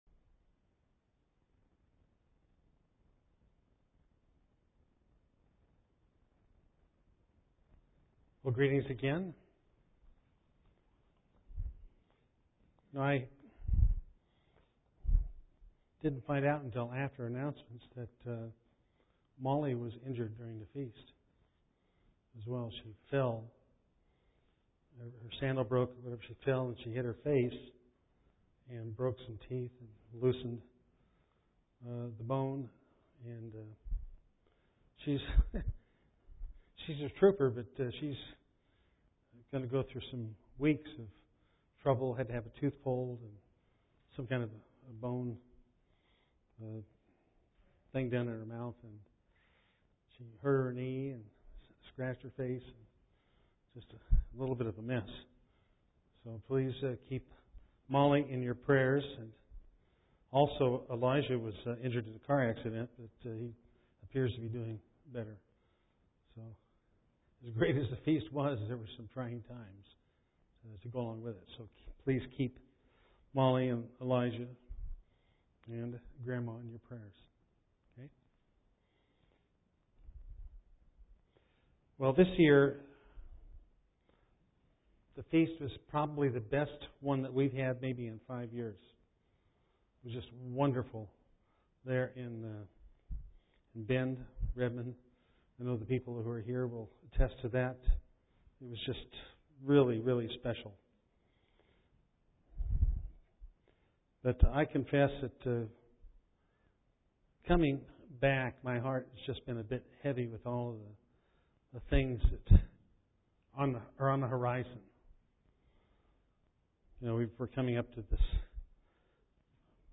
Given in Eureka, CA
Print How to remain unified in unsettled times UCG Sermon Studying the bible?